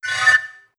menu_open.wav